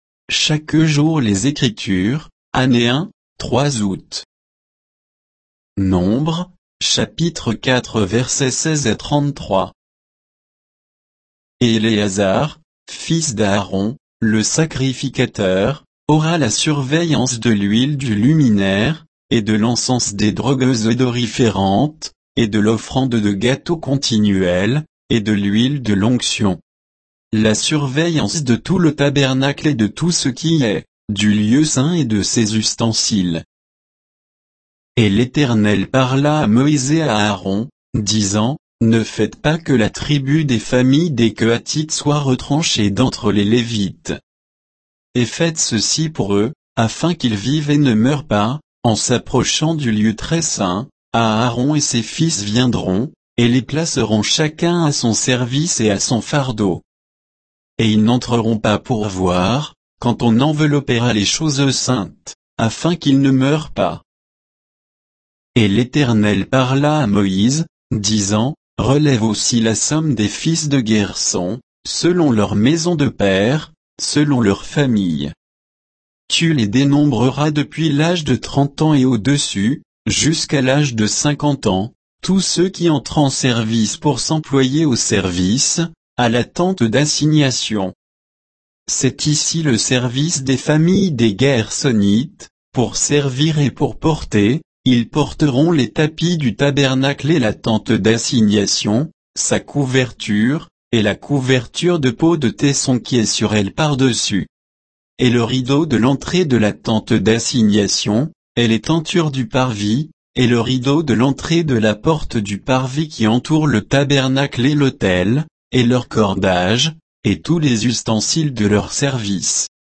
Méditation quoditienne de Chaque jour les Écritures sur Nombres 4